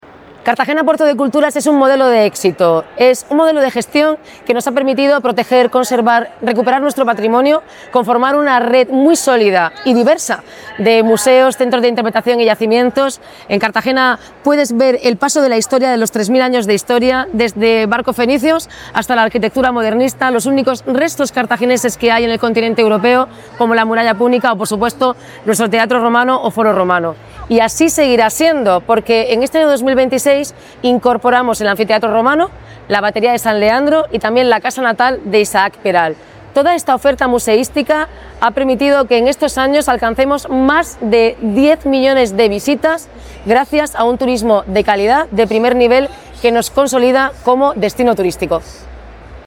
Enlace a Declaraciones de la alcaldesa Noelia Arroyo.